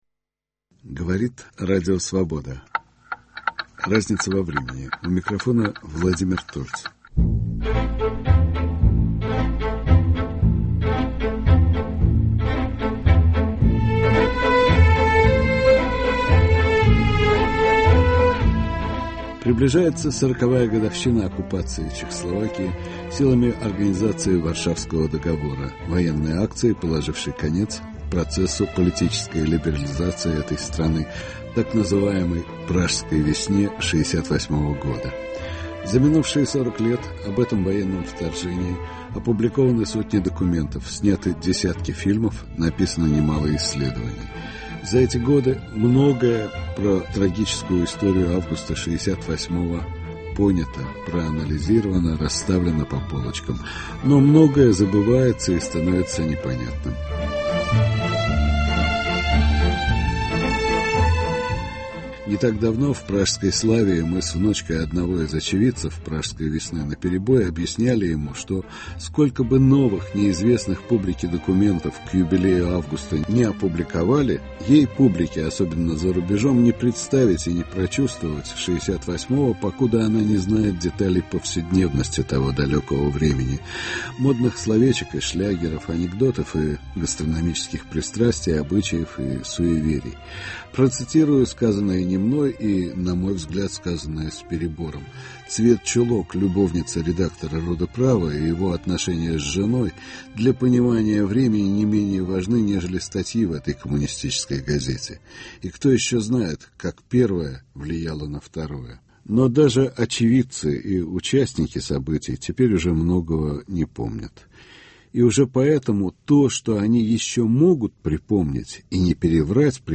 Беседа с бывшей жительницей Праги
записи первого дня советской оккупации ЧССР